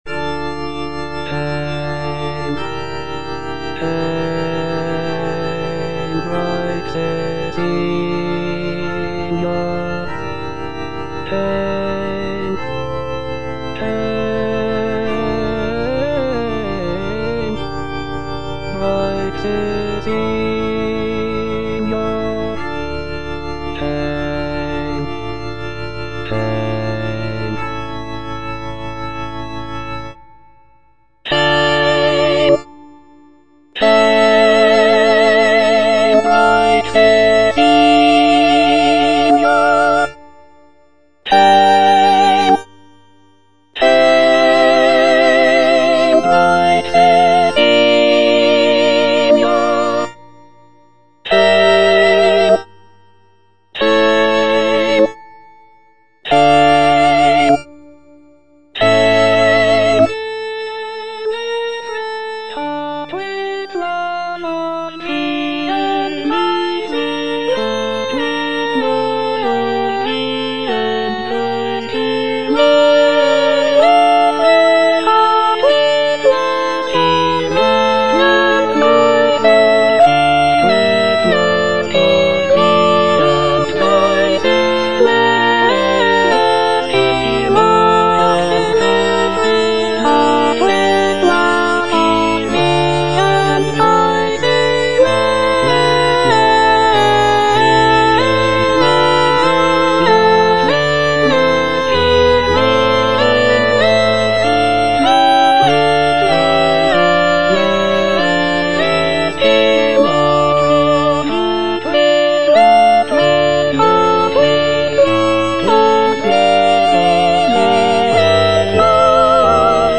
H. PURCELL - ODE FOR ST. CECILIA’S DAY, 1692 Hail, bright Cecilia (I) - Soprano (Emphasised voice and other voices) Ads stop: auto-stop
The ode includes a variety of musical styles, including lively dance rhythms, intricate counterpoint, and expressive melodies.